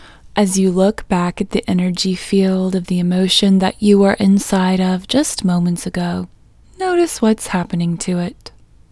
OUT Technique Female English 13